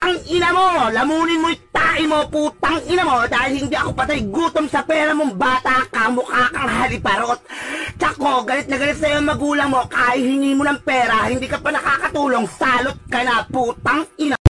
Pinoy yapping
pinoy-yapping.mp3